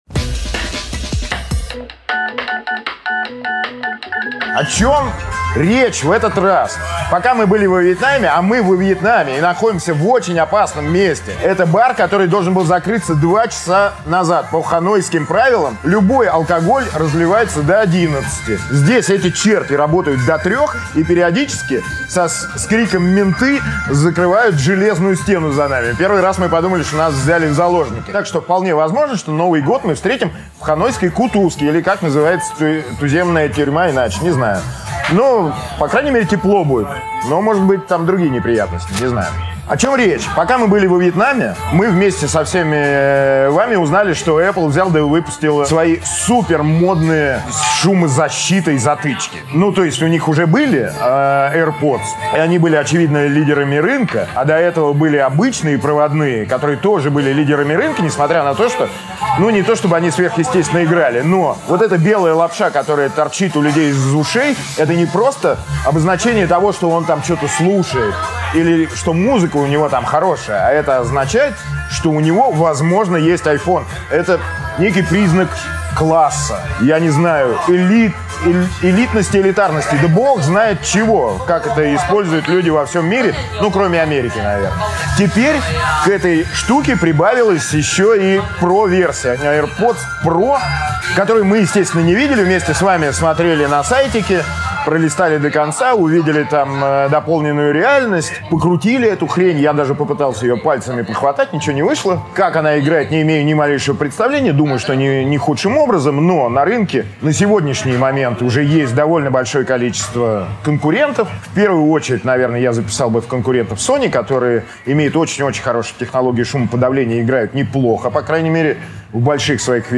Аудиокнига Наркосмартфоны, eSIM в России и комендантский час во Вьетнаме | Библиотека аудиокниг